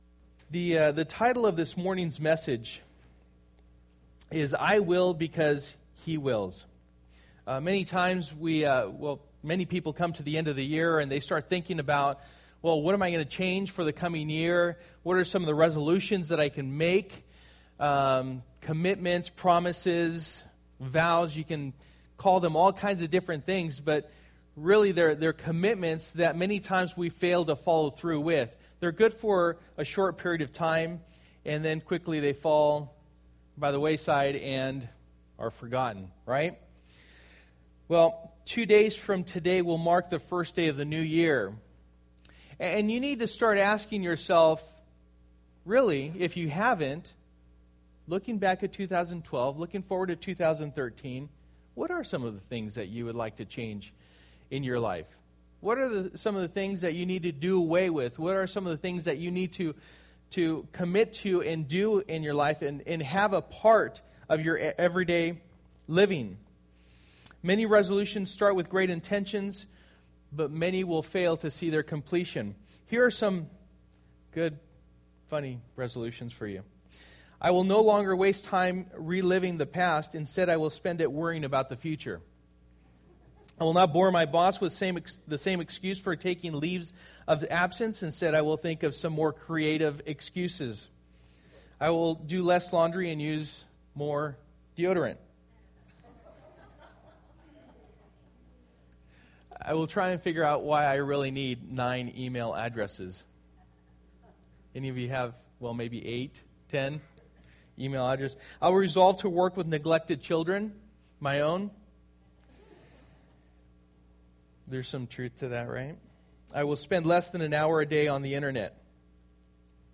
New Year Service: Sunday Morning 2012 New Years Message